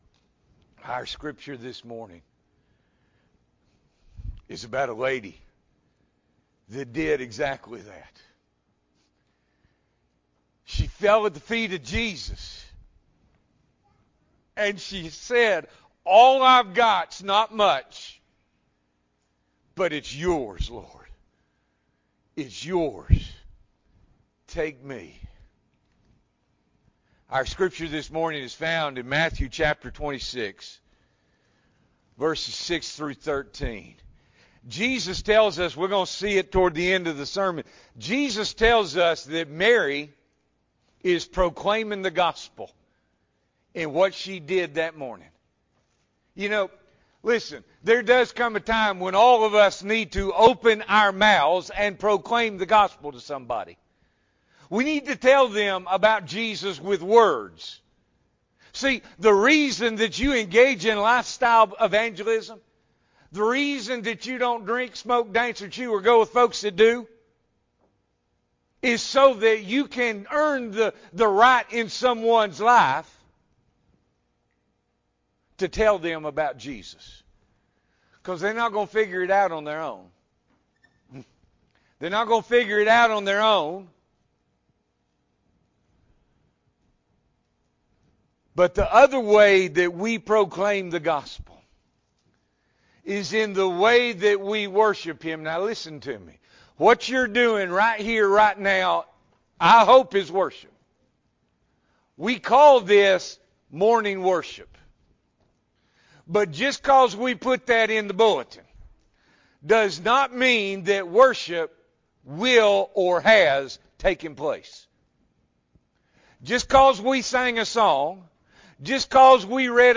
January 24, 2021 – Morning Worship